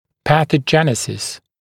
[ˌpæθə’ʤenɪsɪs][ˌпэсэ’джэнисис]патогенез
pathogenesis.mp3